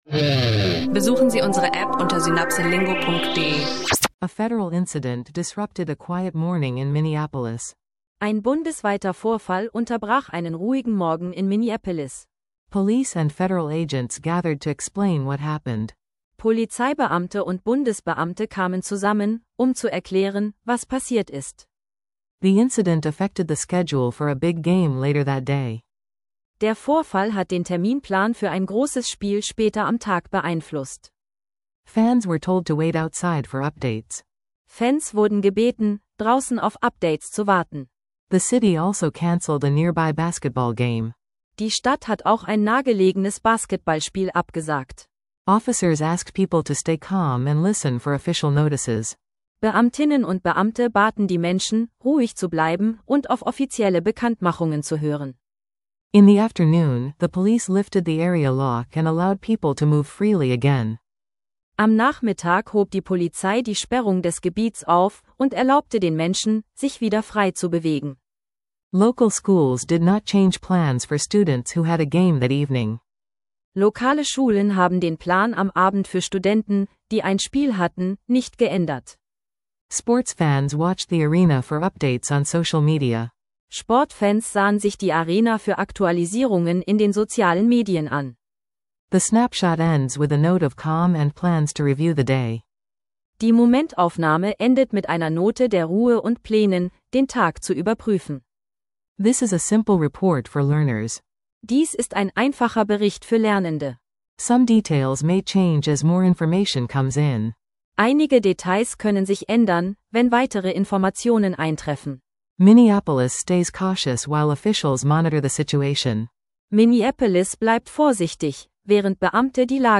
Ein leichter Einstieg ins Englischlernen: Minneapolis News Snapshot erklärt aktuelle Ereignisse verständlich, gefolgt von einem Anfänger- bis Fortgeschrittenen-Dialog über Sport, Fitness und gesunde Lebensweise – perfekt für dein Englisch lernen Podcast.